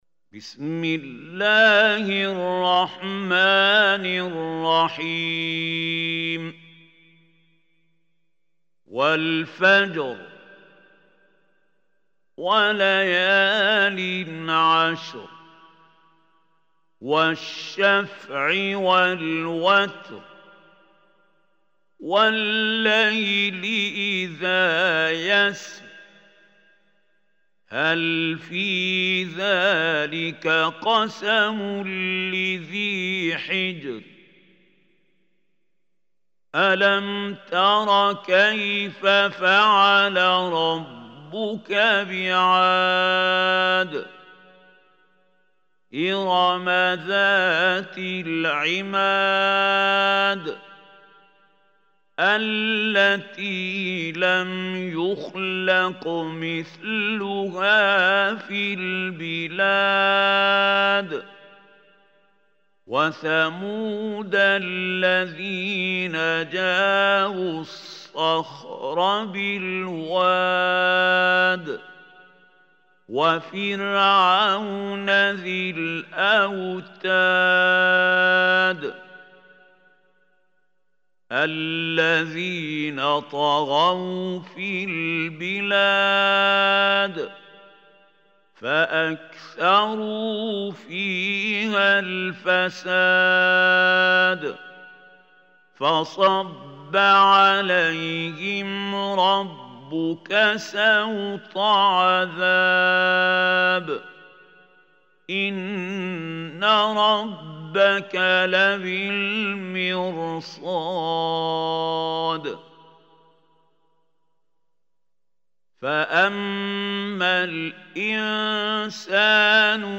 Surah Al Fajr MP3 Recitation by Mahmoud Khalil
Surah Al Fajr, listen or play online mp3 tilawat / recitation in Arabc in the beautiful voice of Sheikh Mahmoud Khalil Hussary.